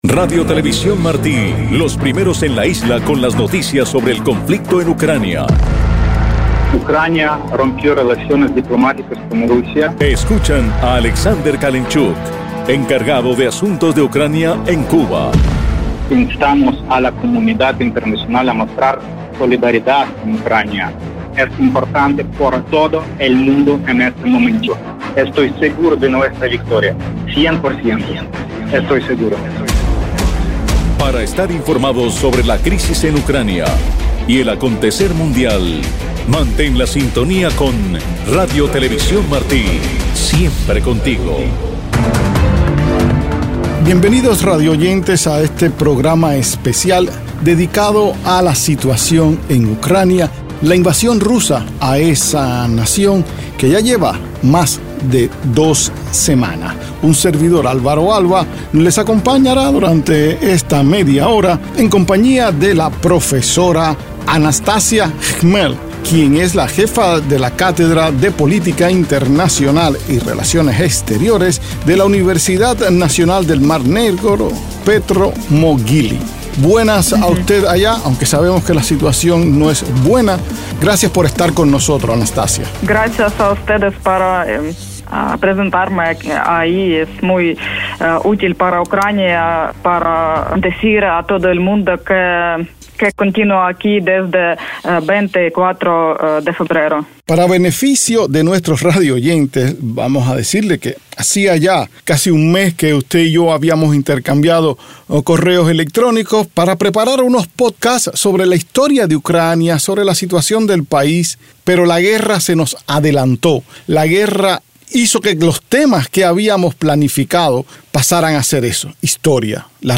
Testimonios de la Guerra en Ucrania: entrevista